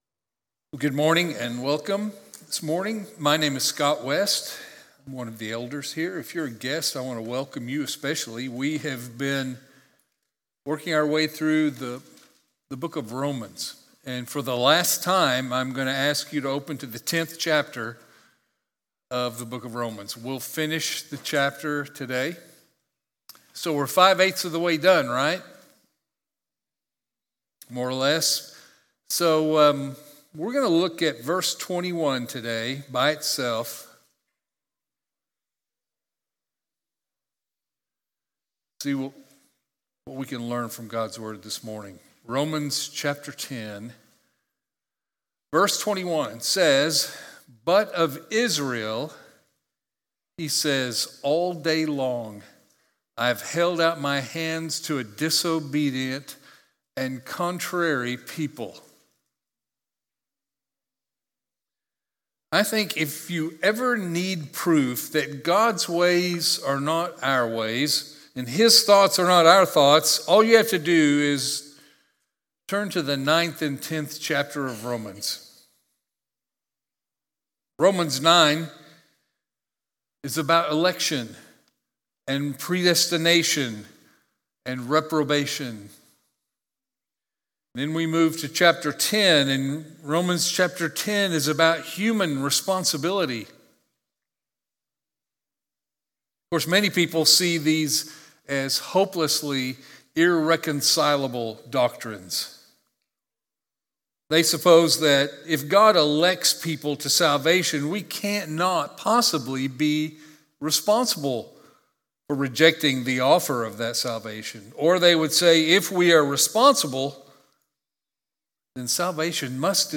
Sermons | Fairway Baptist Church